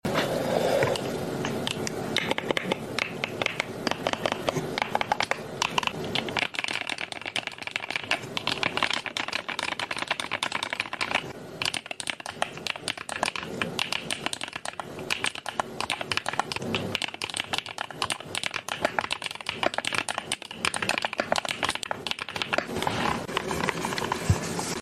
Before recording the colorful meow typing sound before the upgrade, I installed the tiger shaft+opaque key cap, and I took it down again because of Before Recording The Colorful Meow Sound Effects Free Download.